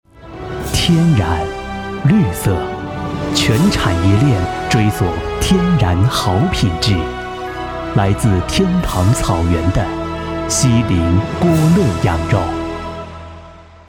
特点：年轻时尚 轻松幽默 MG动画
风格:欢快配音
31男127系列-【品质广告】--牧羊奶.mp3